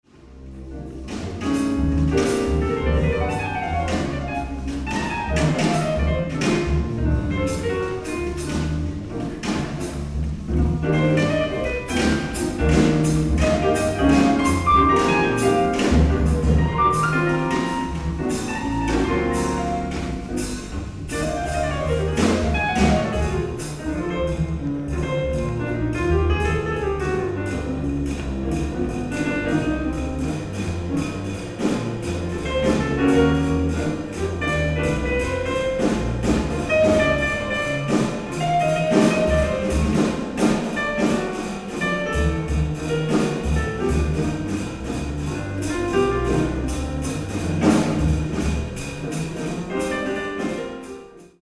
ライブ・アット・ロンドン、イングランド 07/20/1992
※試聴用に実際より音質を落としています。